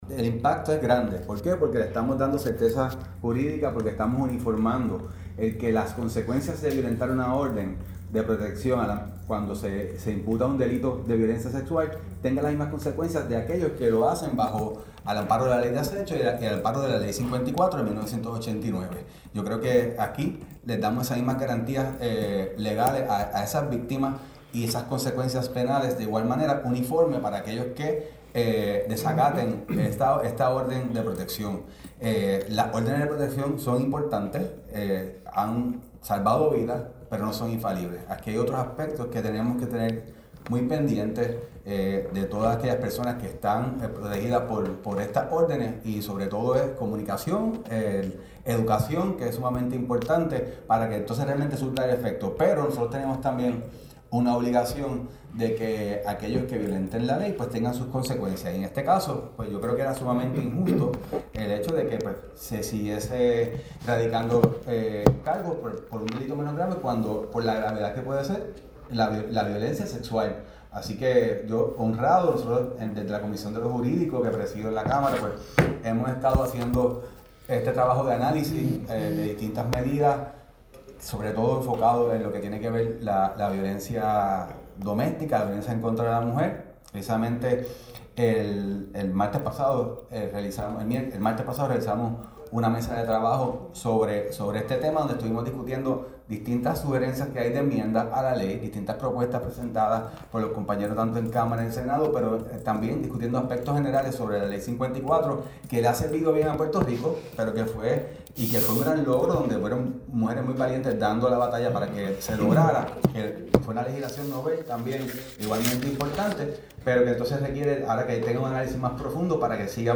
El representante, Pérez explicó en conferencia de prensa “En Récord” que esta nueva ley propone tipificar como delito grave el incumplimiento de órdenes de protección emitidas a favor de víctimas de violencia sexual, reforzando así el marco legal existente y garantizando mayores salvaguardas para quienes han sido sobrevivientes de este tipo de agresión.